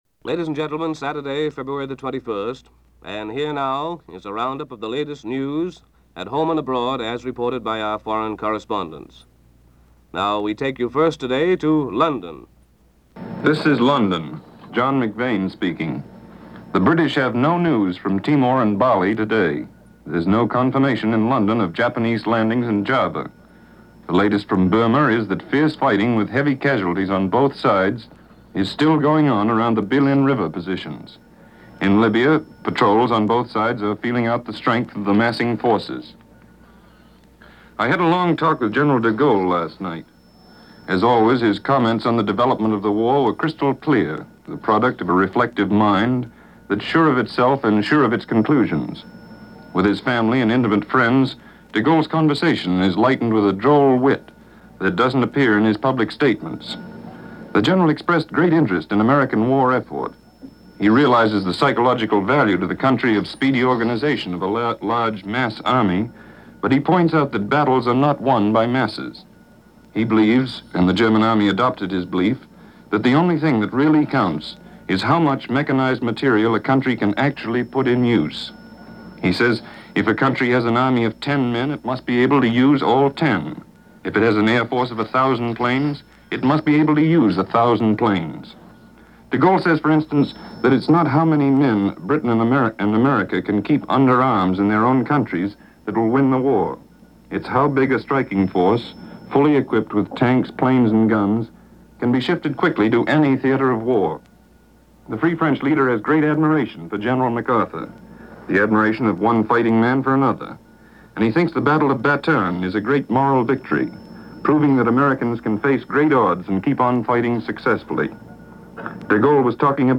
News for this day in 1942 and comment from Martin Agronsky.